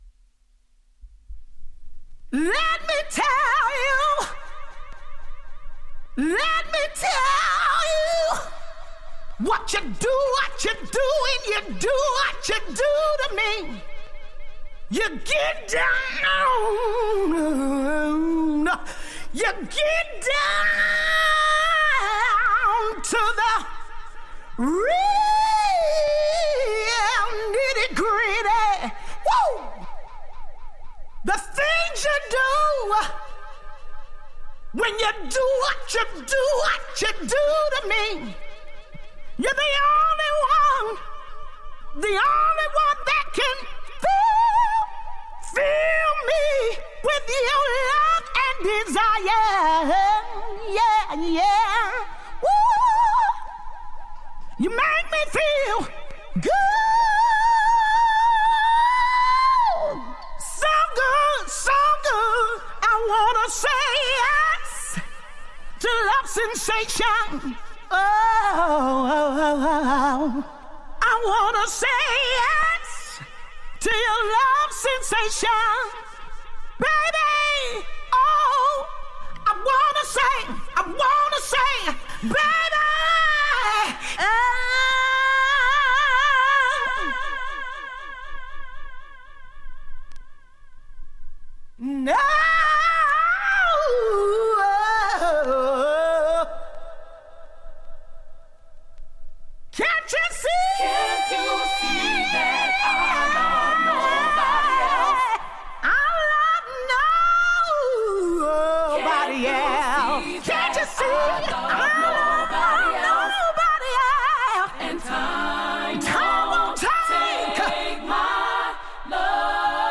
ACCAPELLA